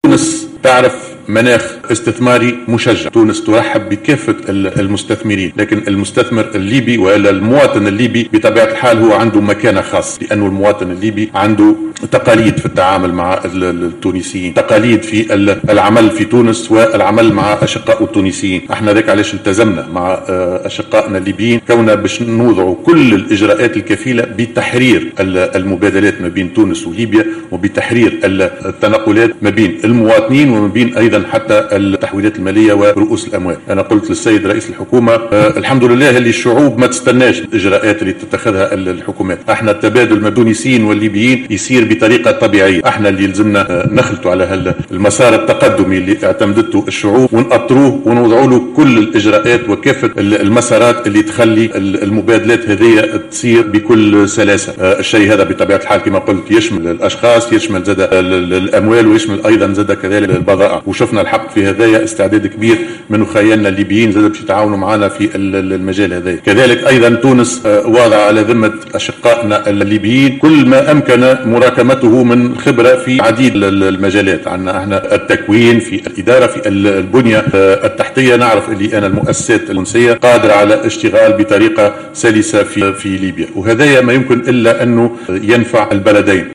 قال رئيس الحكومة هشام مشيشي خلال الندوة الصحفية التي جمعته اليوم السبت بنظيره رئيس حكومة الوحدة الوطنية الليبية عبد الحميد الدبيبة، إن تونس تعرف مناخا استثماريا مشجعا، وإن البلاد ترحب بكافة المستثمرين الأجانب وعلى رأسهم المستثمرون الليبيون.